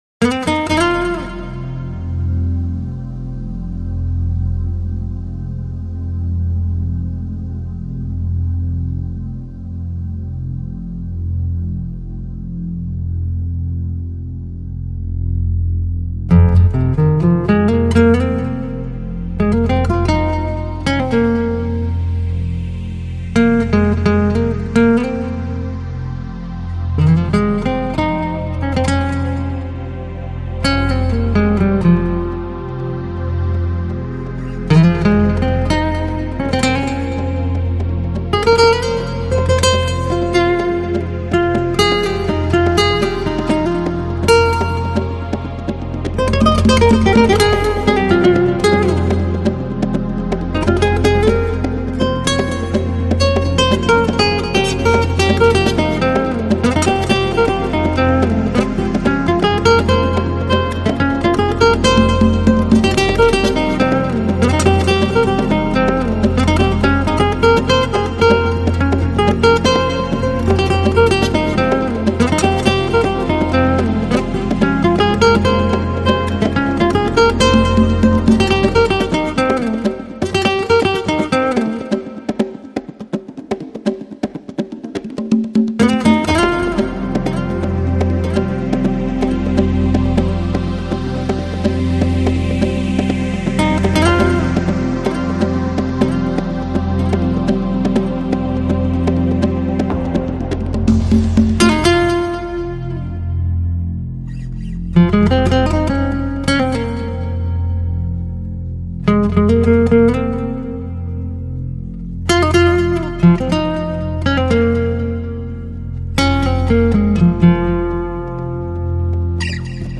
Жанр: Chillout, Chillhouse, Downbeat, Lounge